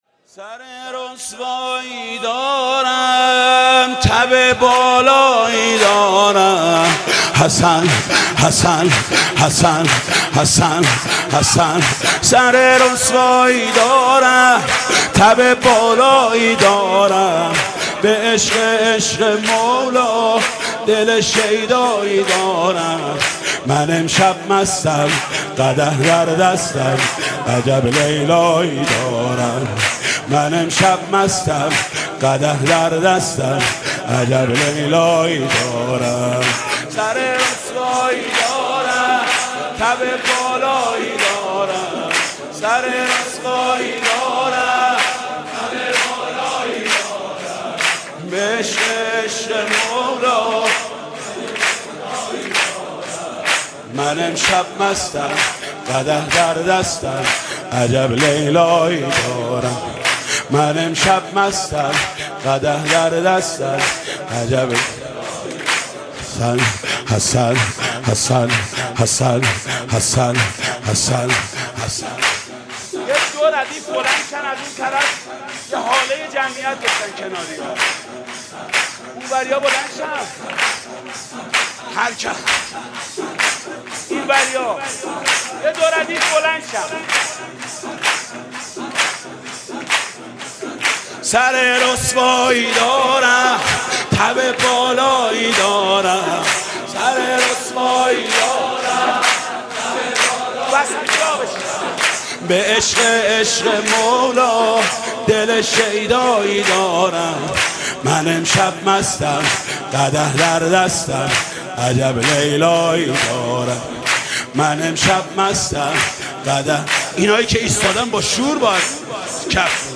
سرود: سر رسوایی دارم تب بالایی دارم